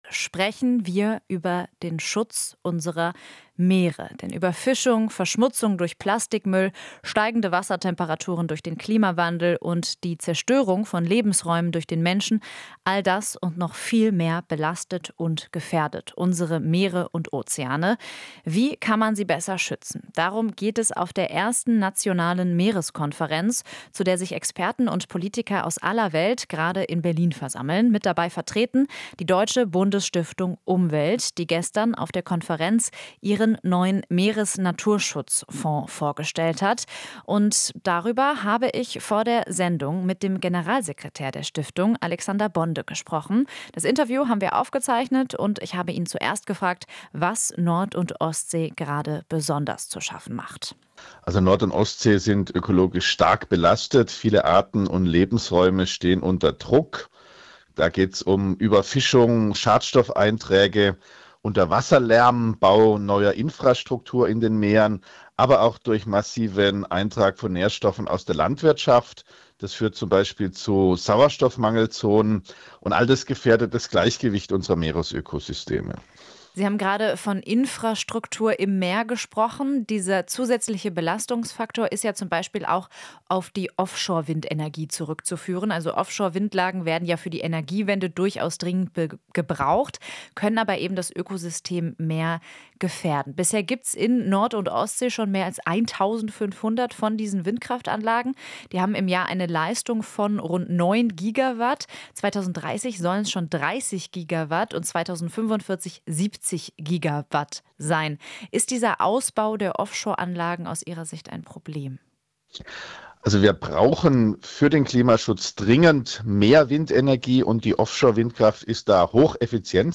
Interview - Meereskonferenz: Wie können Nord- und Ostsee besser geschützt werden?